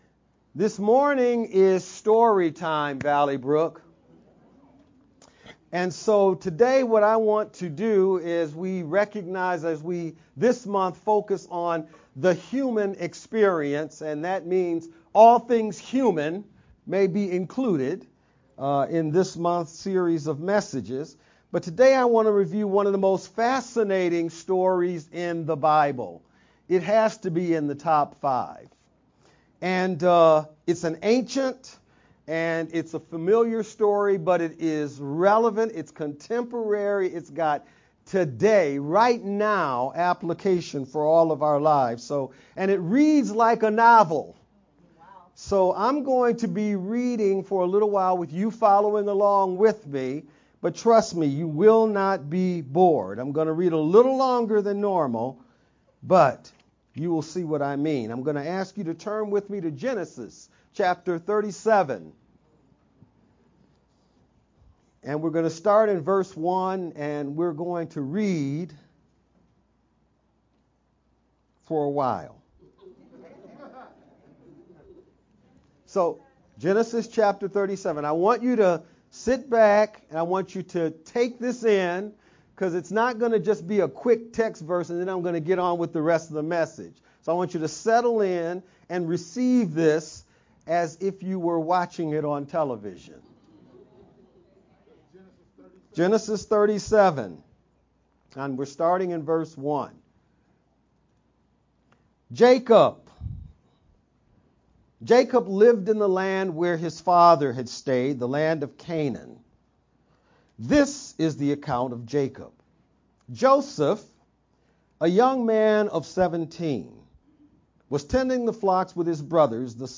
October-15th-Sermon-only-Sermon-only_Converted-CD.mp3